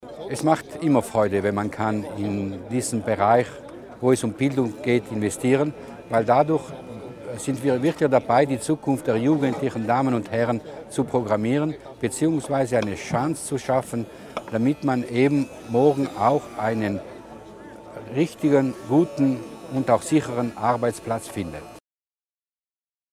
Landesrat Berger erklärt die Bedeutung der Ausbildung auch im Tourismusbereich